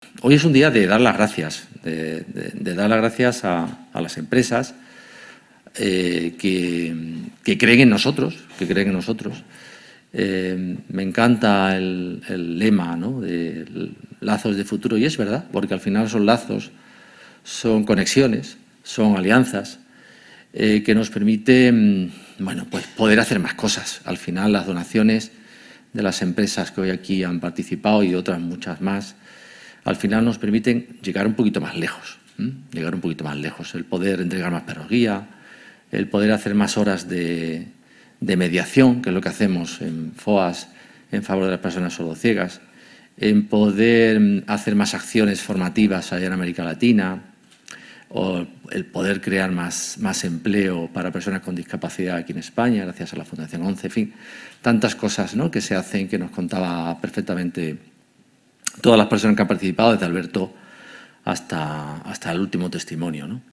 La sede madrileña de Fundación ONCE reunió a estas 25 empresas y entidades en el  acto ‘Lazos de futuro’, celebrado el 18 de noviembre de manera presencial y telemática, con el objetivo de expresar públicamente el agradecimiento de las fundaciones del Grupo Social ONCE a los particulares, empresas e instituciones que colaboran con ellas a través de programas, proyectos e iniciativas que ayudan a avanzar en la igualdad de los derechos de las personas con discapacidad y sus familias, y por su compromiso con la mejora de la calidad de vida de las personas con discapacidad.